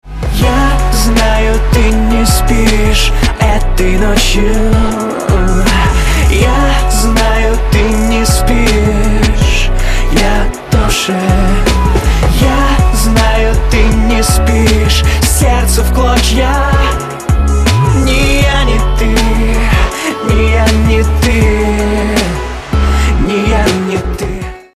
мужской вокал
лирика
RnB
Hp Hop